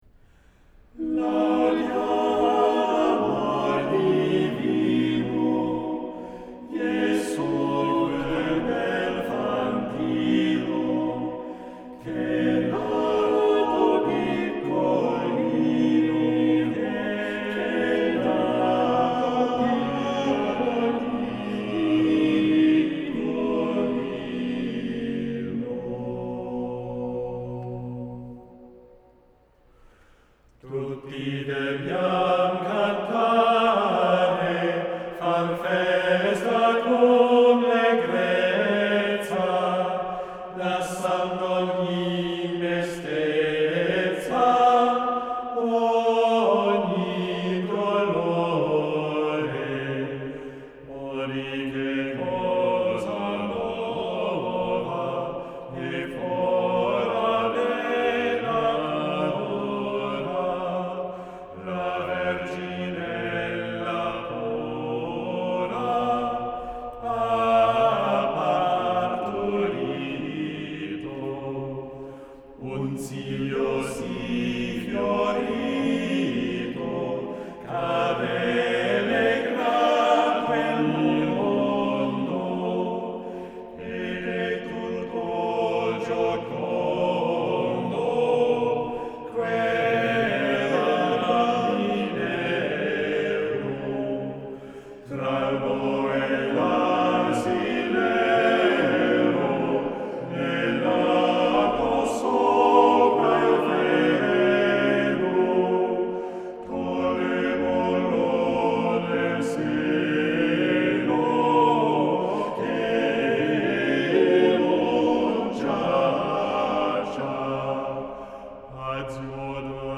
devotional song